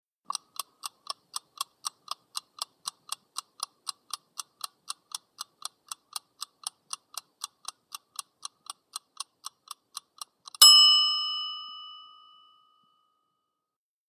Egg Timer | Sneak On The Lot
HOUSEHOLD EGG TIMER: INT: Ticking and ping.